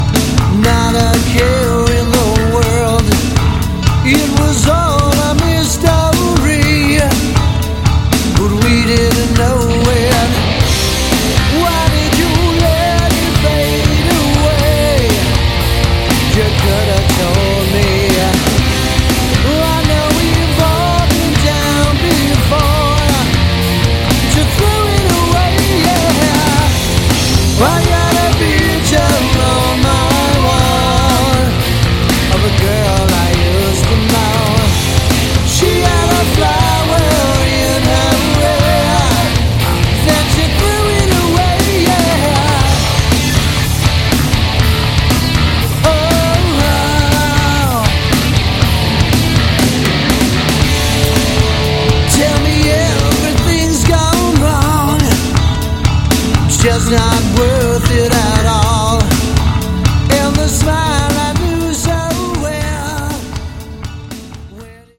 Category: Hard Rock
vocals, guitars
guitar
bass
drums